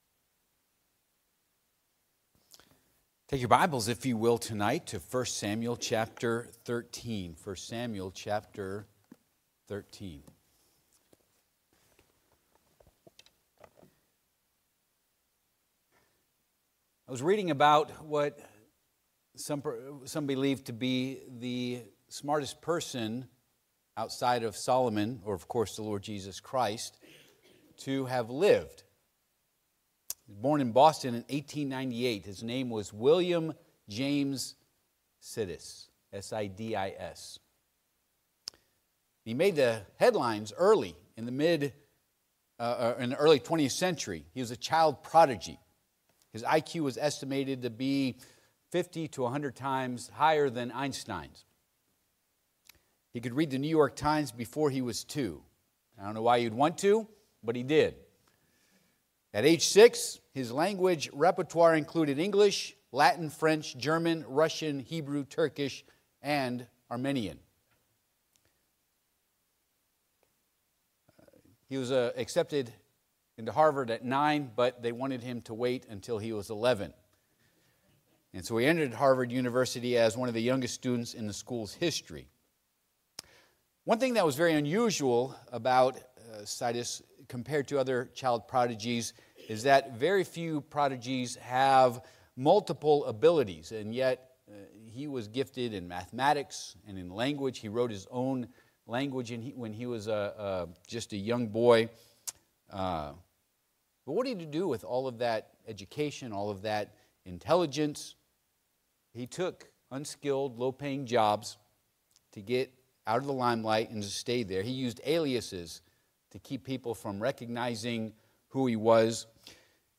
I Sam. 13:1-3 Service Type: Midweek Service « Origin of the Bible pt. 1 Parting out the Promised Land